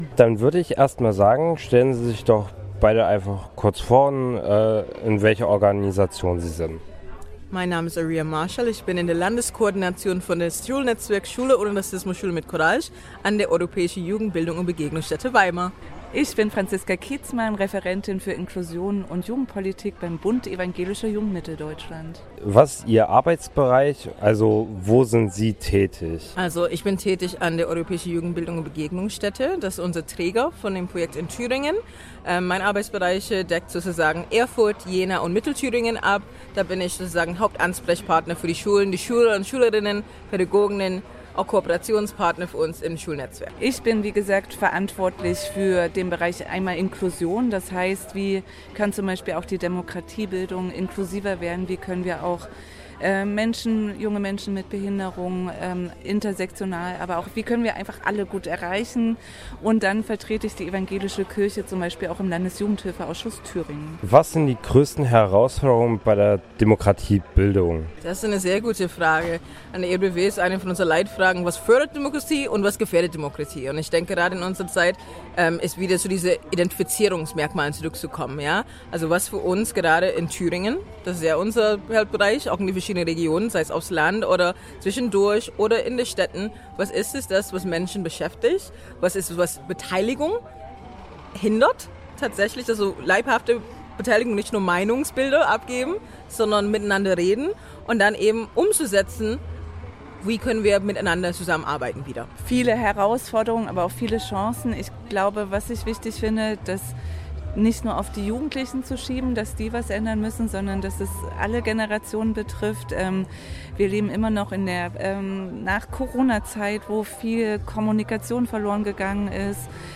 Sommerfest des Netzwerks Demokratiebildung Thüringen | Stimmen zum Nachhören
Radio F.R.E.I. und das Jugendforum Erfurt waren vor Ort und haben mit verschiedenen Beteiligten gesprochen.
Zwei Vertreterinnen von Partnerorganisationen des Netzwerks